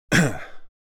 Download Free Cough Sound Effects | Gfx Sounds
Male-throat-clearing.mp3